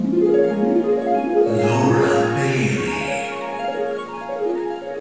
Play, download and share *harp sound* Laura Bailey original sound button!!!!
harp-sound-laura-bailey.mp3